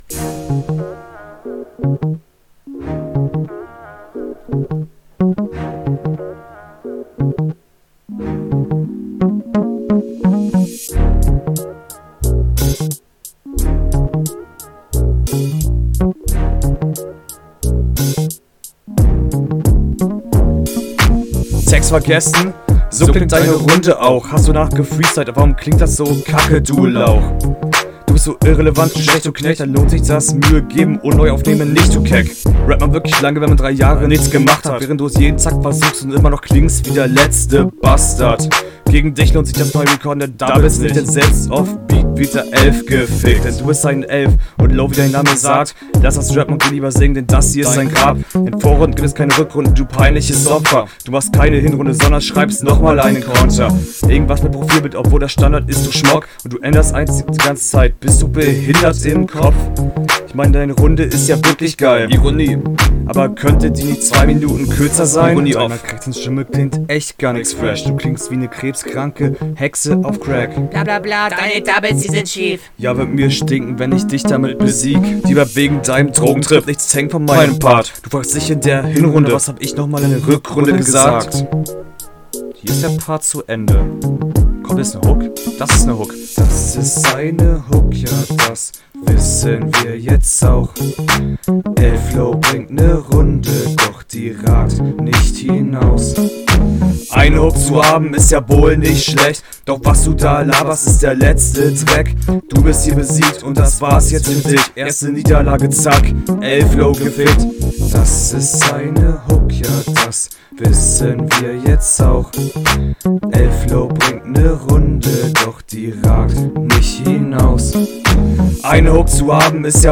besser als erste runde, stimme is nice, wird dope mit bisschen mehr routine und erfahrung …